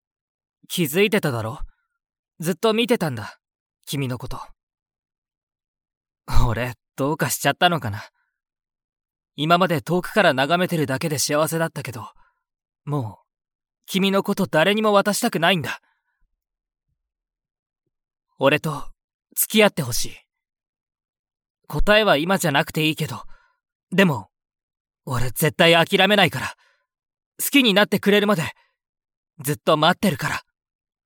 我们的日语外籍配音人员全部是母语为日语的外籍人士，发音地道，音质圆润。